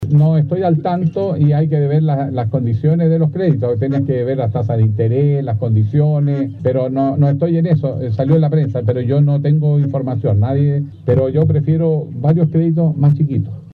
Las declaraciones las realizó mientras visitaba el Campamento Manuel Bustos de Viña del Mar, donde compartió con los vecinos una tallarinata.